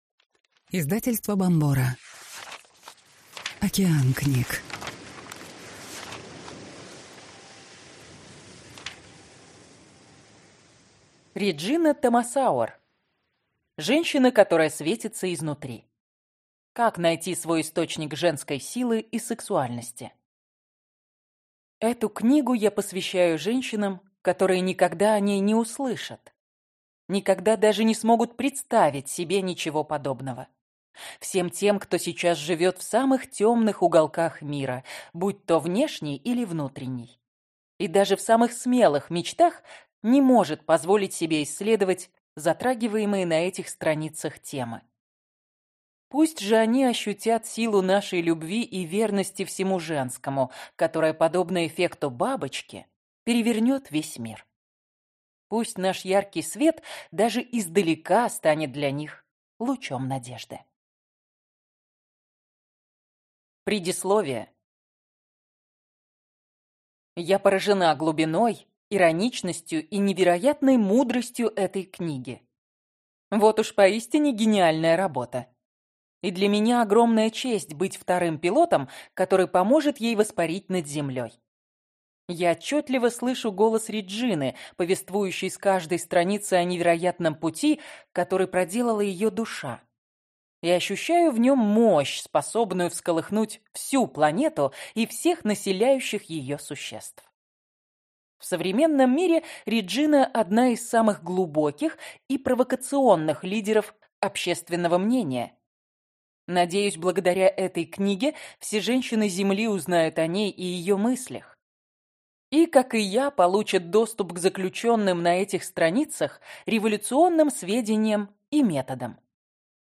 Аудиокнига Женщина, которая светится изнутри. Как найти свой источник женской силы и сексуальности | Библиотека аудиокниг